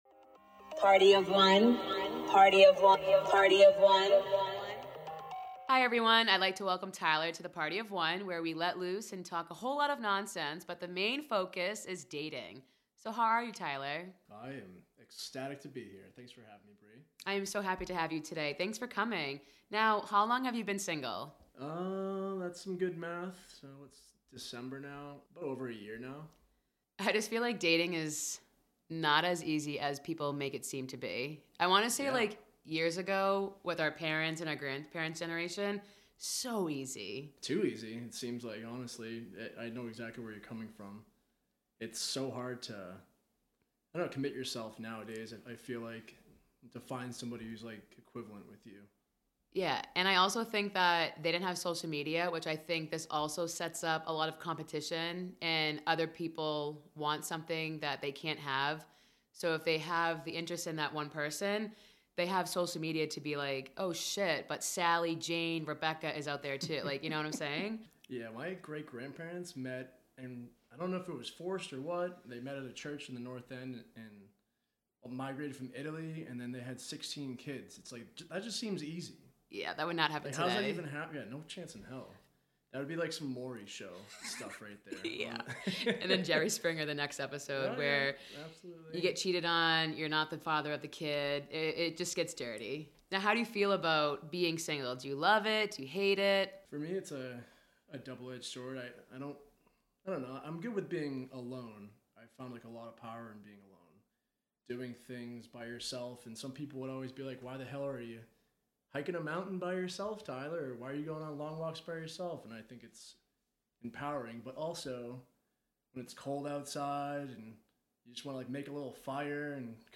Welcome to the PartyOfOne podcast where we let loose and talk a whole lot of random, raw, funny things about dating and the single life! Each week I will interview single people and hear their experiences, tips, and laugh at everything.